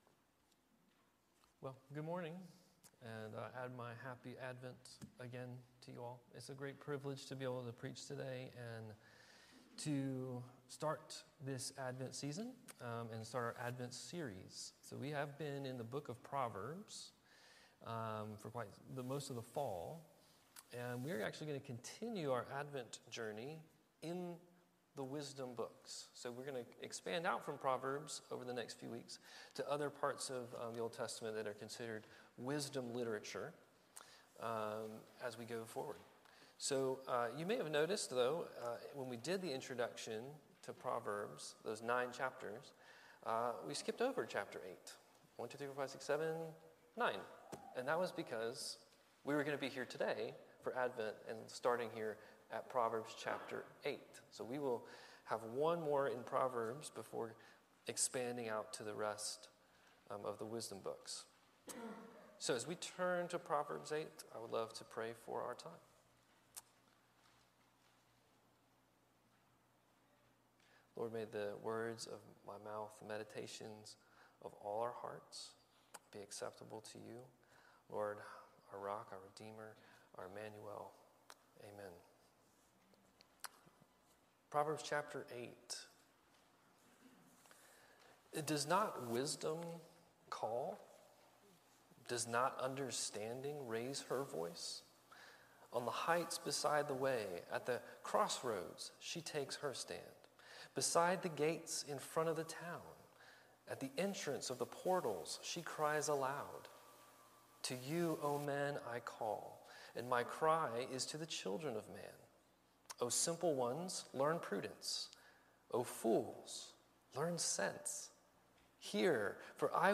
Sermon and Teaching